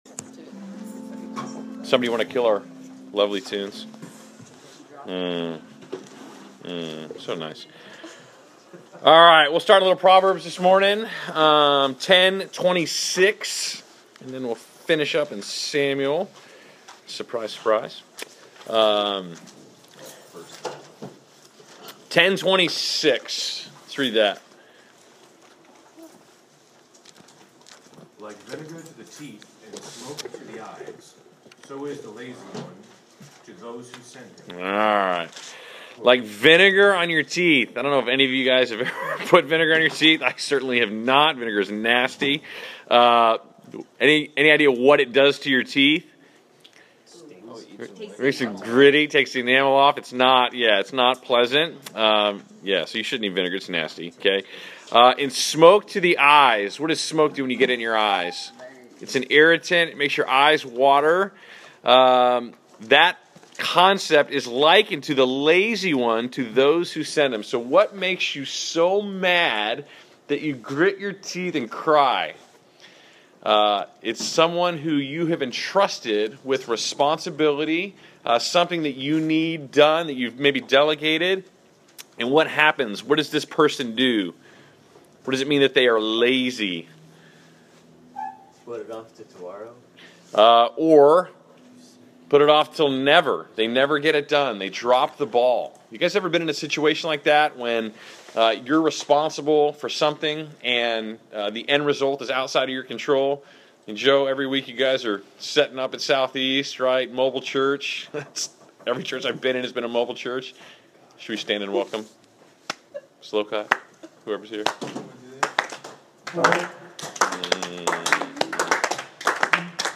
Class Session Audio March 09